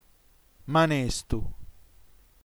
manestu nm ma.né.stu - [ma'nestu] ◊